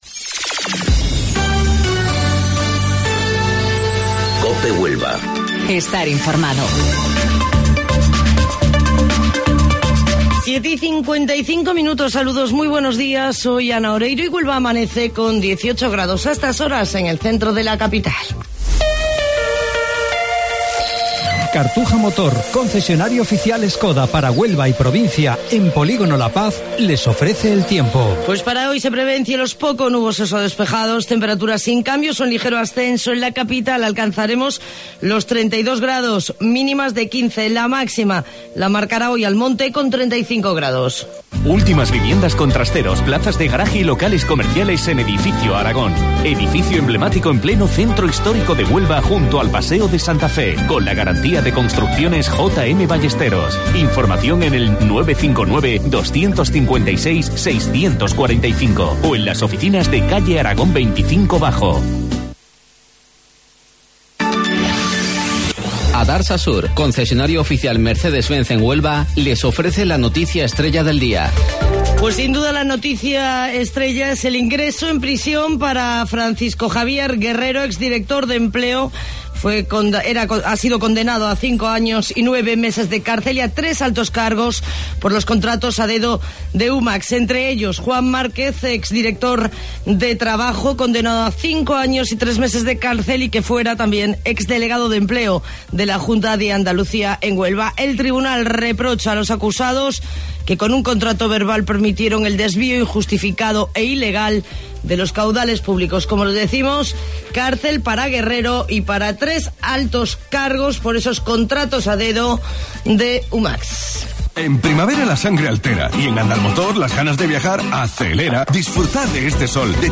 AUDIO: Informativo Local 07:55 del 31 de Mayo